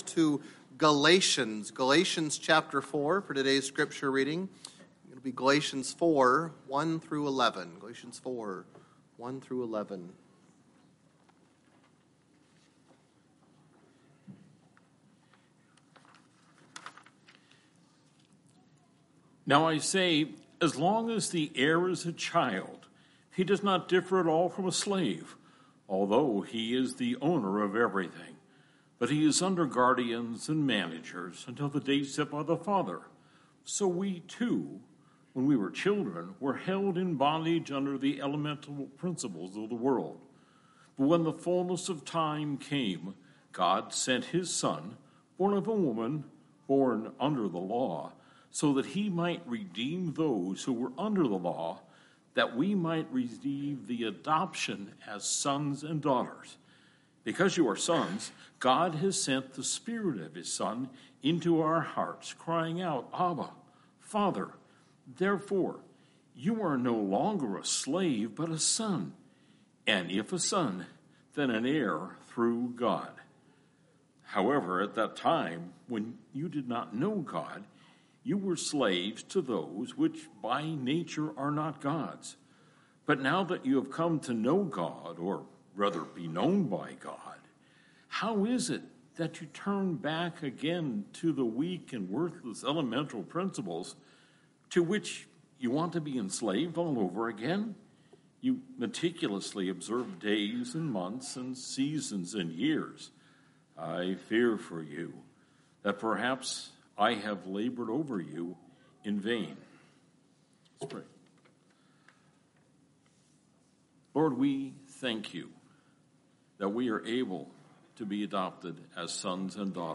Past Sermons - Kuna Baptist Church